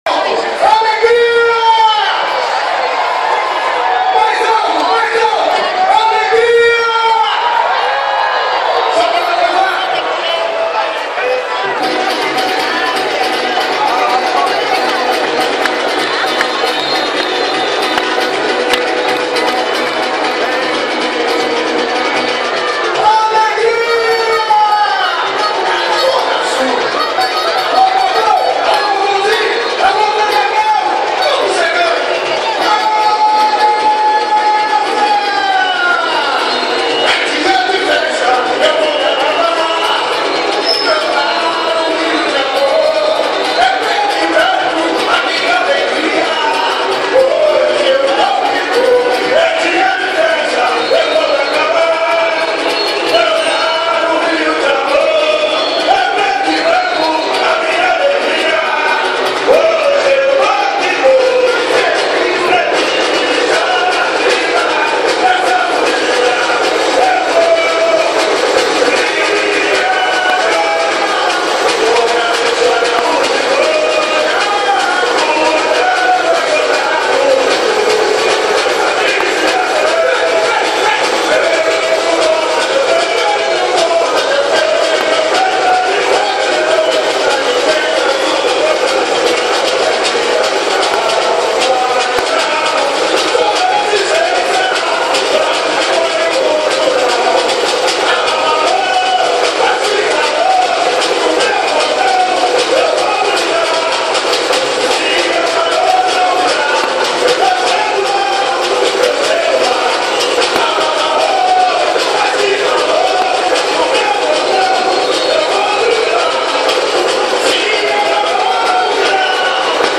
Infelizmente, um problema crônico da escola se manifestou: a falta de canto das alas.